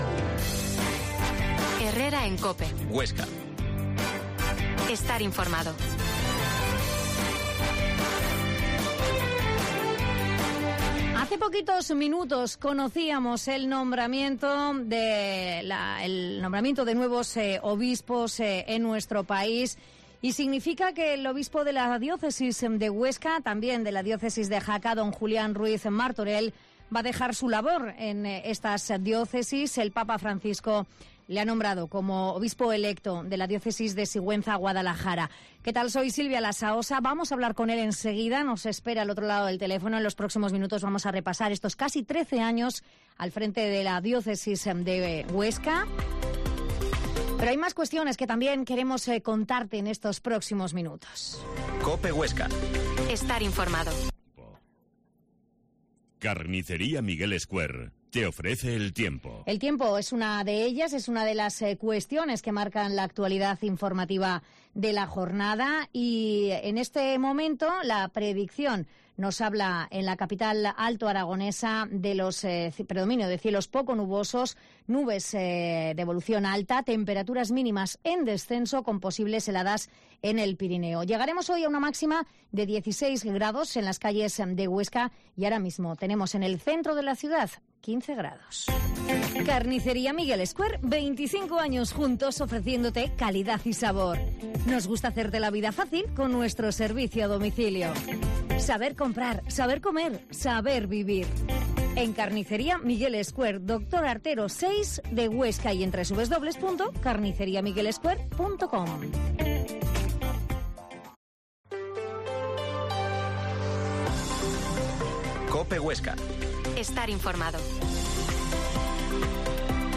Herrera en COPE Huesca 12.50h Entrevista al obispo D. Julián Ruiz Martorell